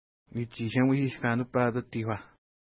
Pronunciation: wi:tʃi:tʃəmuʃi:ʃ ka:nəpa:t ati:kwa: